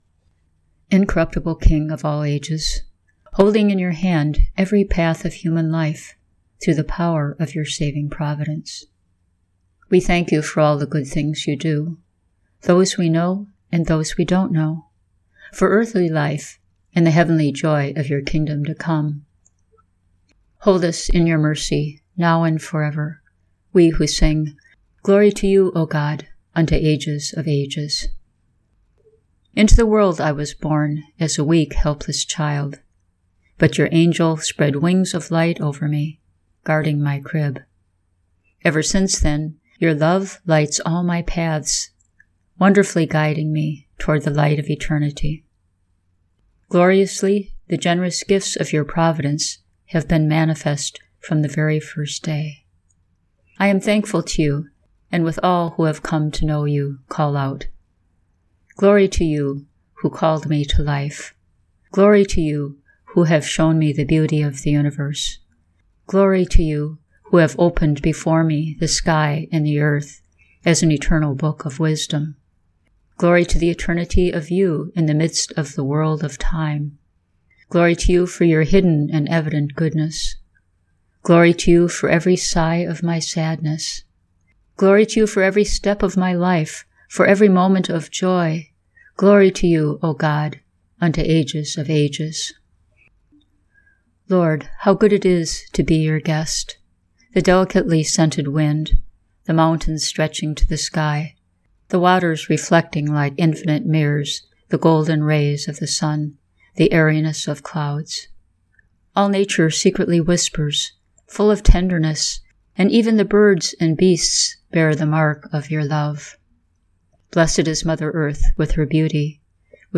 I am not trained to sing or chant so I read it.
akathist-of-thanksgiving2.mp3